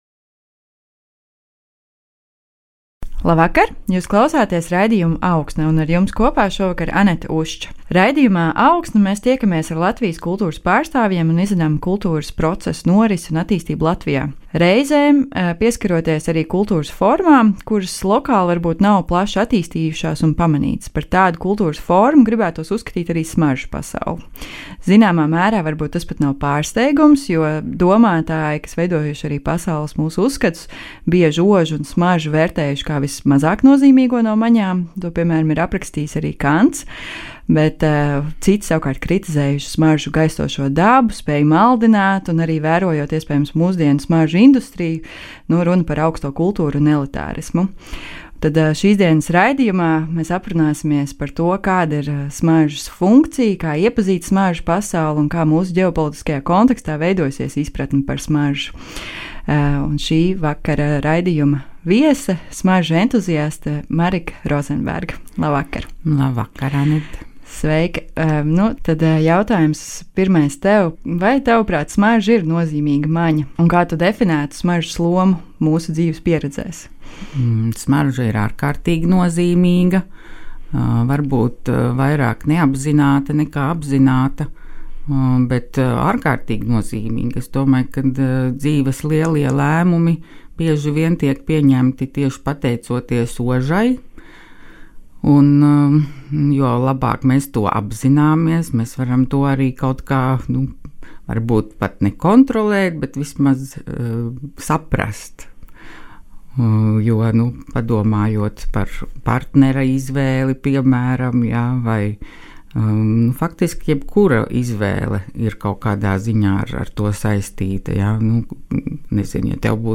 Nemanāmā smaržas pasaule. Saruna